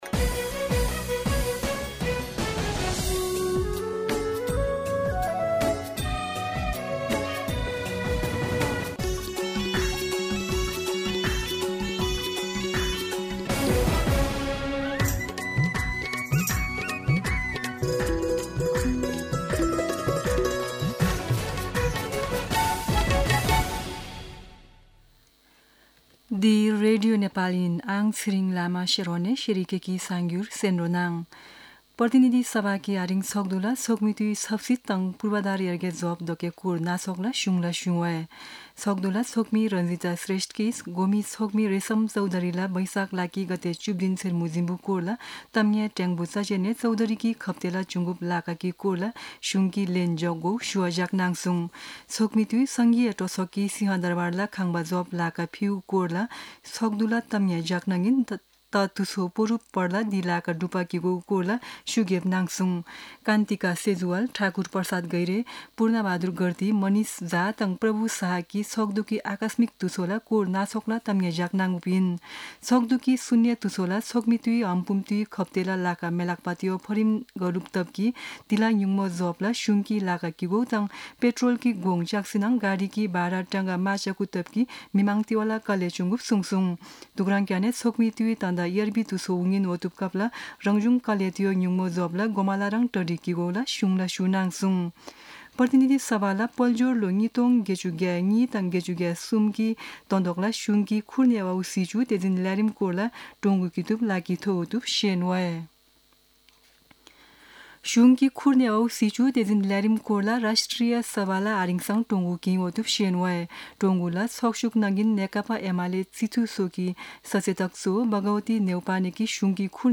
शेर्पा भाषाको समाचार : २३ वैशाख , २०८२
Sherpa-News-01-23.mp3